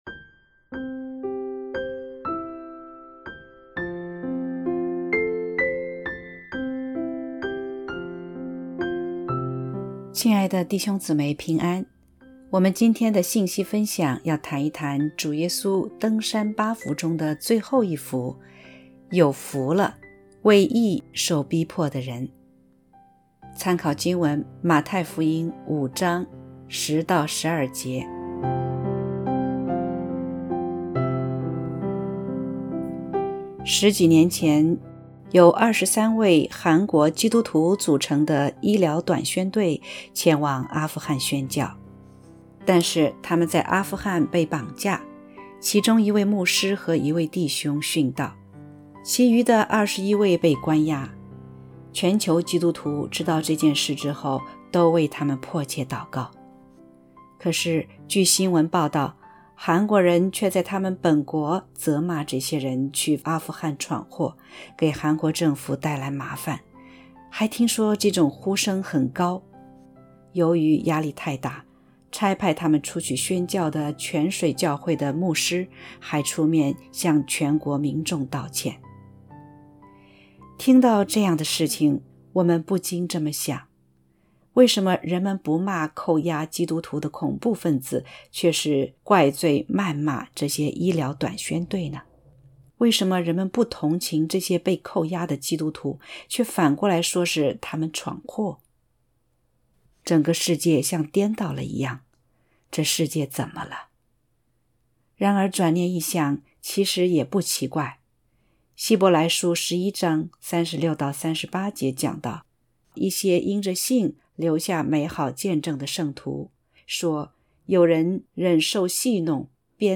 （合成）D登山八福8——有福了，为义受逼迫的人.mp3